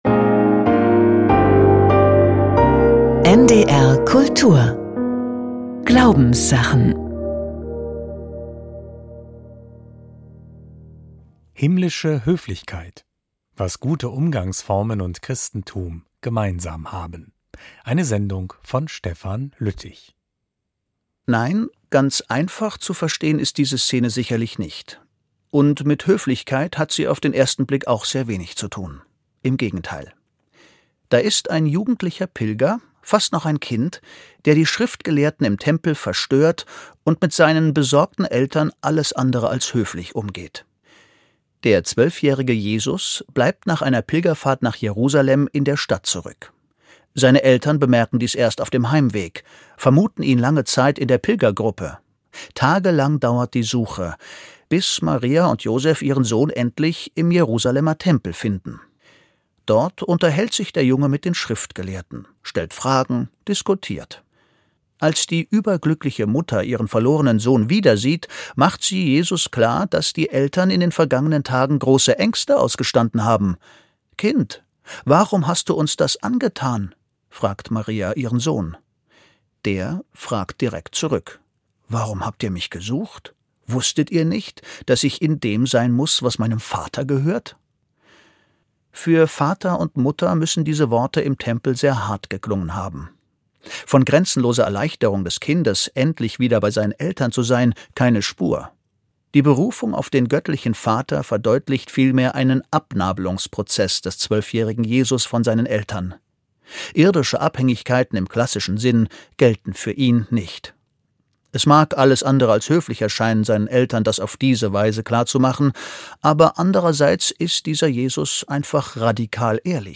Die Kunst des Zuhörens - Gespräch mit Bernhard Pörksen - 02.02.2025